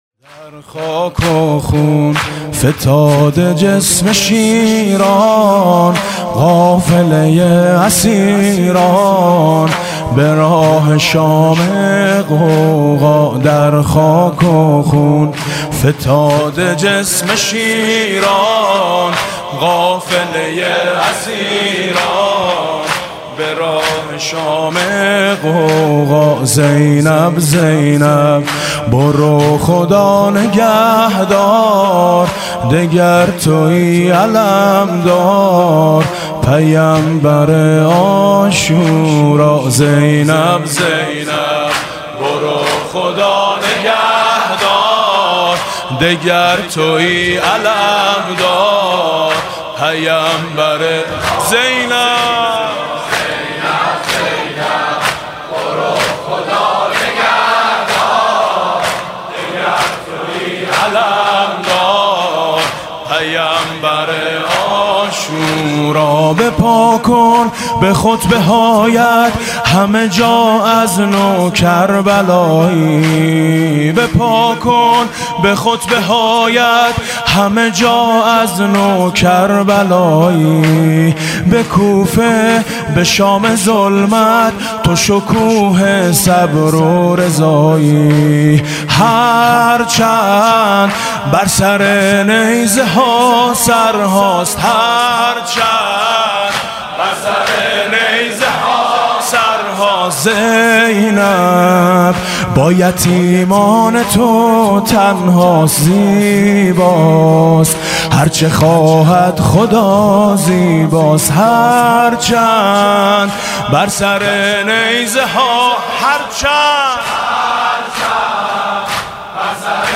«محرم 1396» (شب پانزدهم) زمینه: در خاک و خون فتاده جسم شیران